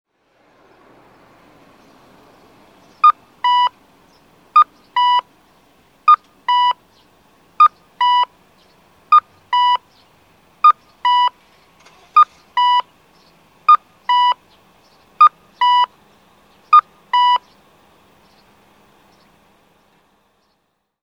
R9亀岡警察署前(京都府亀岡市)の音響信号を紹介しています。